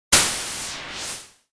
SE（風魔法 風）
風魔法。疾風。